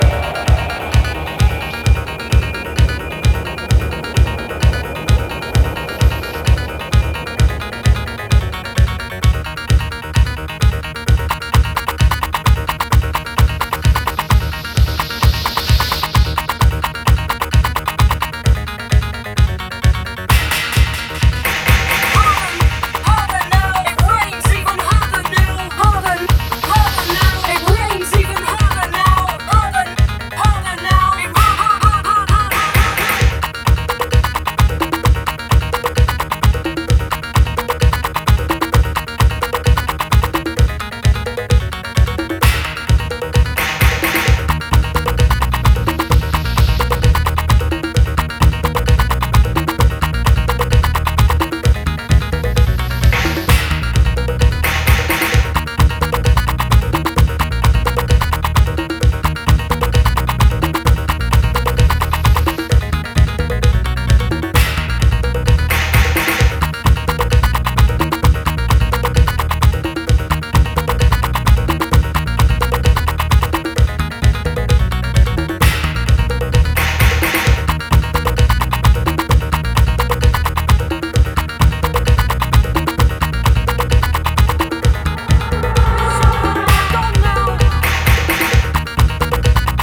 Todas las pistas están remasterizadas para la serie.